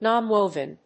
/ˈnɑˈnwovɪn(米国英語), ˈnɑ:ˈnwəʊvɪn(英国英語)/